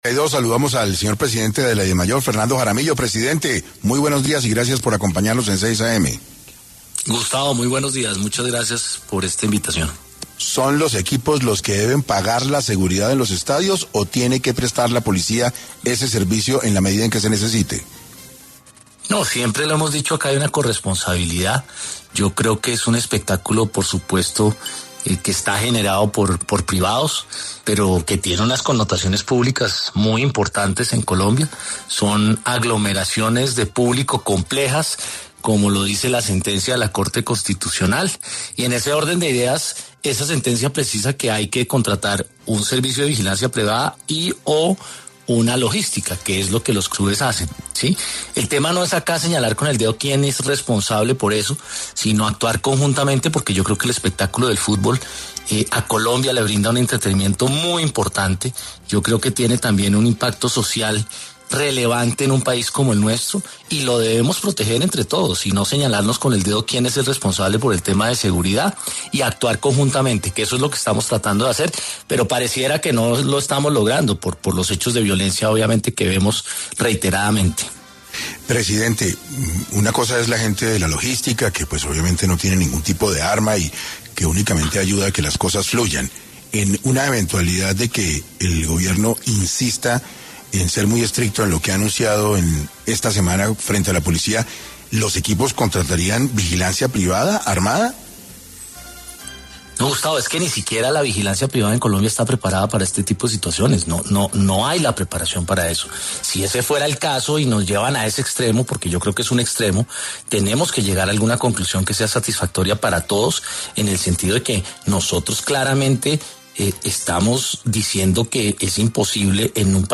expresó en el noticiero ‘6AM’ de Caracol Radio su preocupación sobre los actos de violencia sucedidos en la final de la Copa Colombia.